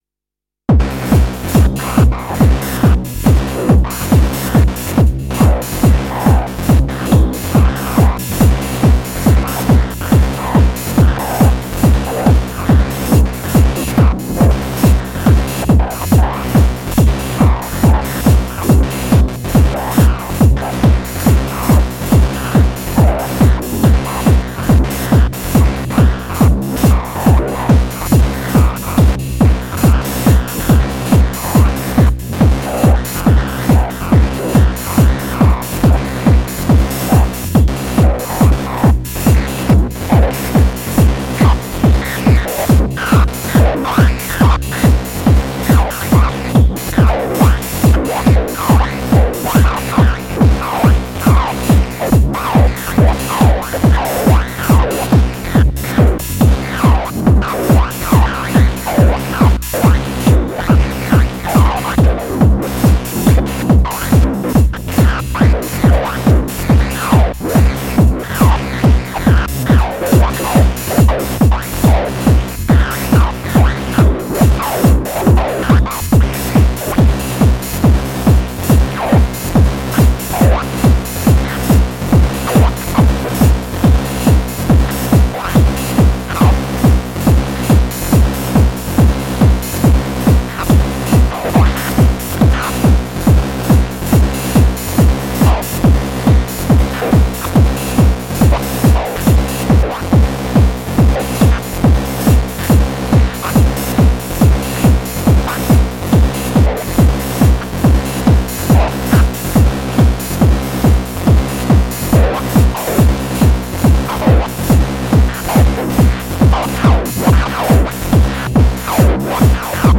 More silliness with OT, Polivoks and Sherman.